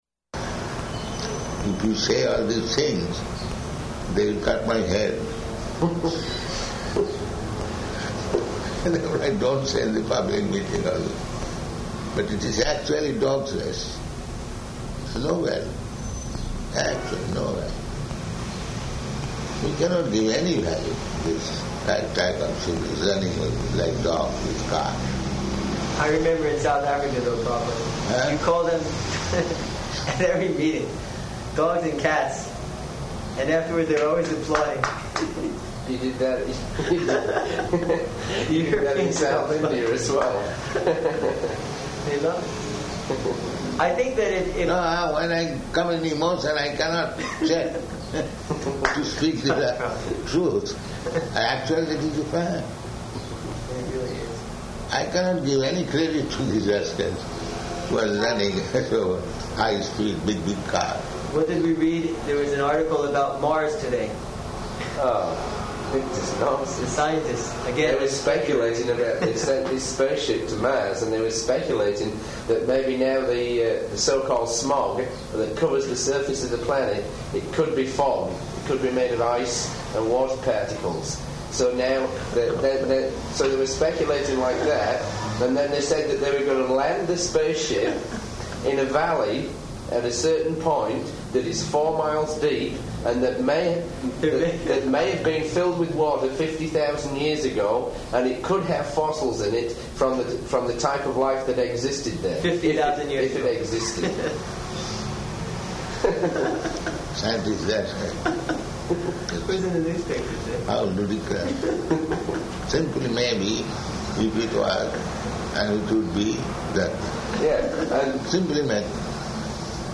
Room Conversation
Room Conversation --:-- --:-- Type: Conversation Dated: June 18th 1976 Location: Toronto Audio file: 760618R1.TOR.mp3 Prabhupāda: If we say all these things, they will cut my head.